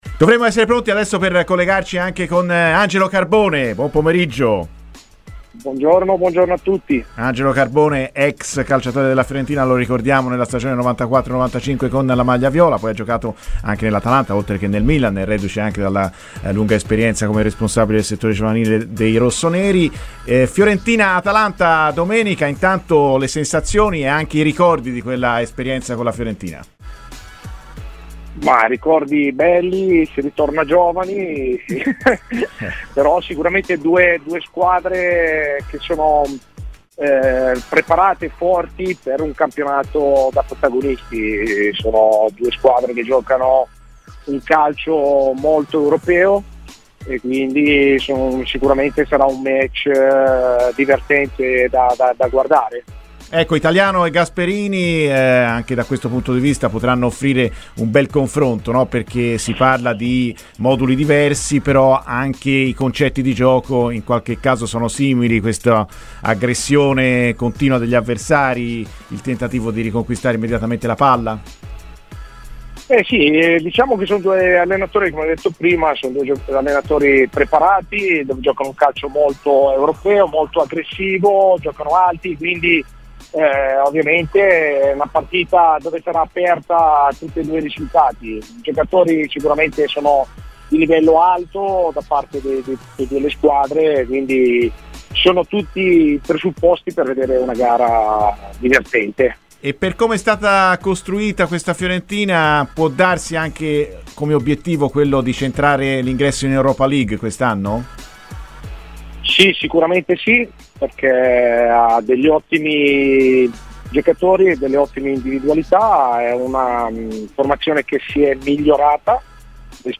Angelo Carbone, centrocampista doppio ex di Fiorentina e Atalanta, è intervenuto ai microfoni di RadioFirenzeViola, durante la trasmissione 'Viola amore mio', parlando del match di domenica: "E' una partita che mi fa riaffiorare molti ricordi.